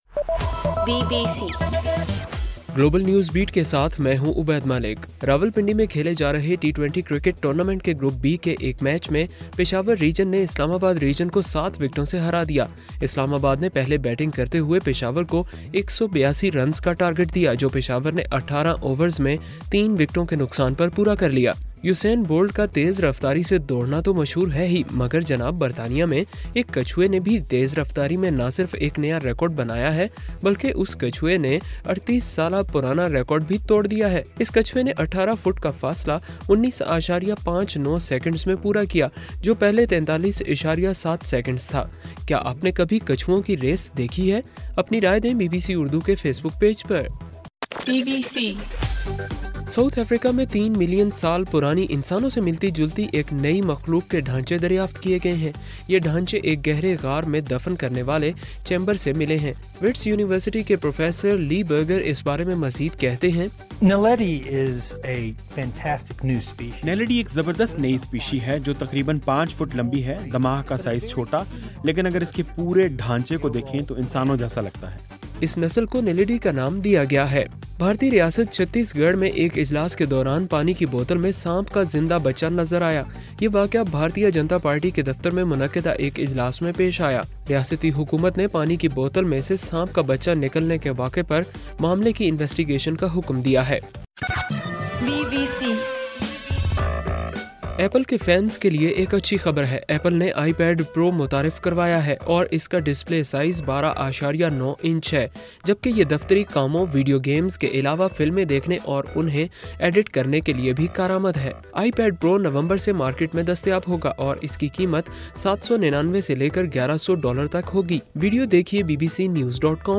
ستمبر 10: رات 10 بجے کا گلوبل نیوز بیٹ بُلیٹن